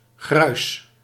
Ääntäminen
Ääntäminen France: IPA: [pudʁ] Haettu sana löytyi näillä lähdekielillä: ranska Käännös Ääninäyte Substantiivit 1. poeder {m} 2. pulver 3. toiletpoeder 4. gruis Suku: f .